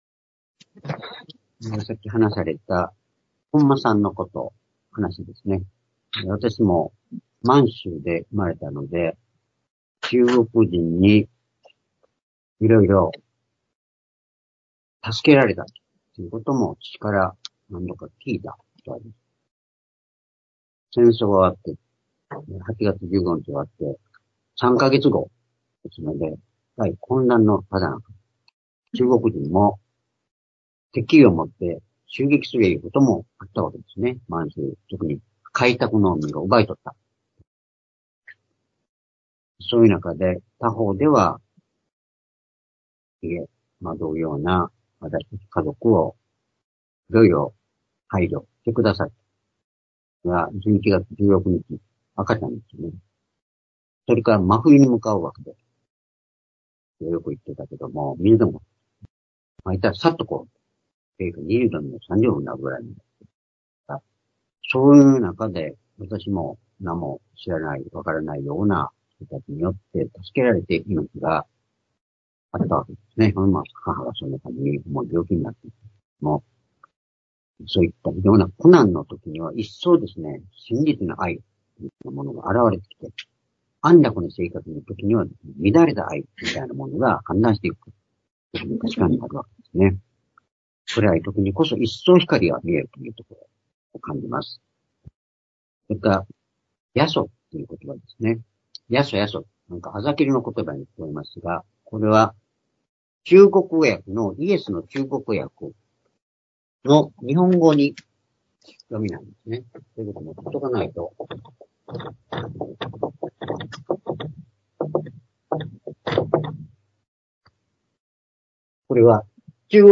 主日礼拝日時 ２０２５年1月２６日（主日礼拝） 聖書講話箇所 「十字架にかかわる３種類の人々」 ヨハネ19の23-27 ※視聴できない場合は をクリックしてください。